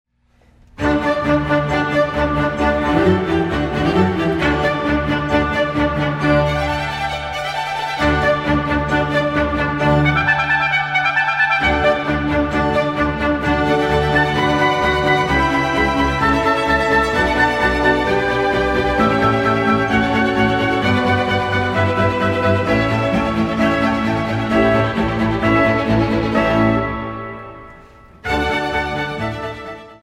orgue, clavecin
soprano
• Studio : Église Saint-Matthias